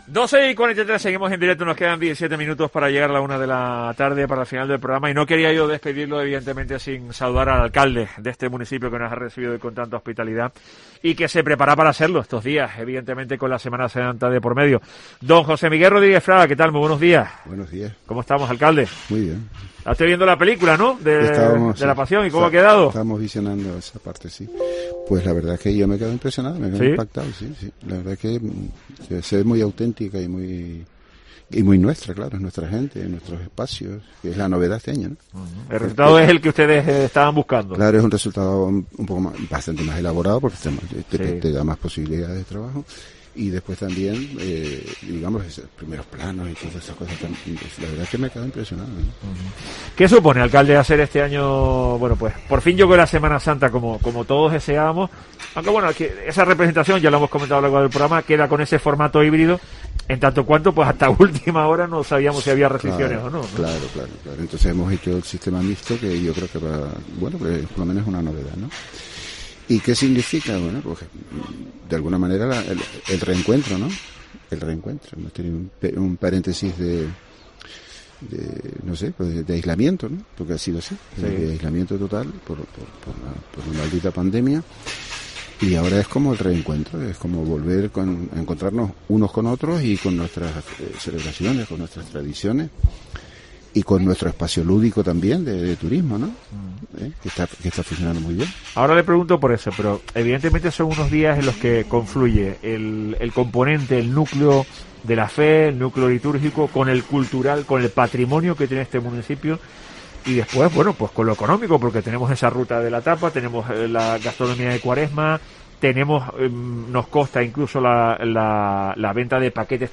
Entrevista a José Miguel Rodríguez Fraga, alcalde de Adeje